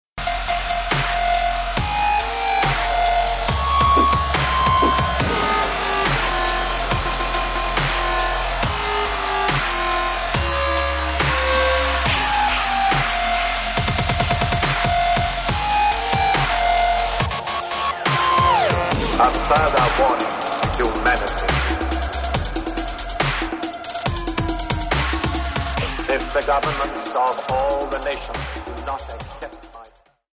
140 Dubstep Mix